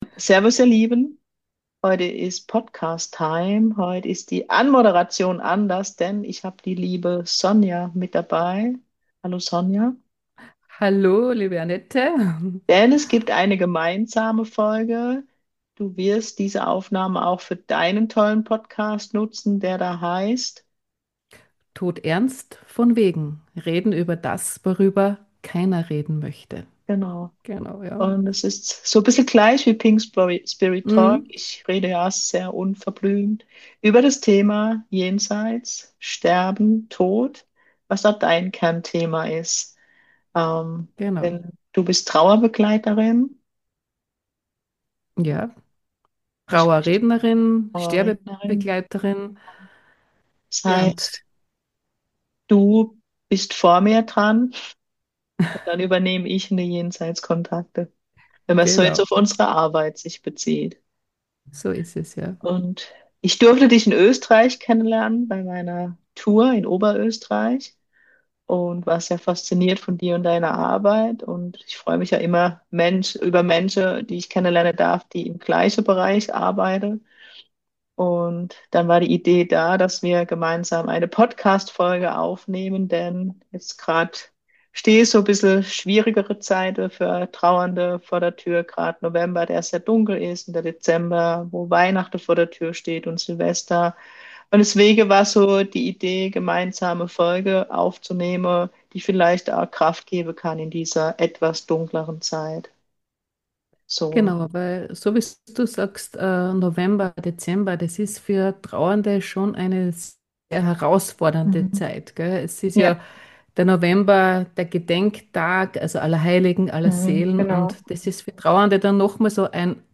Ein Gespräch, das uns behutsam in eine tiefere, tröstliche Ebene mitnimmt.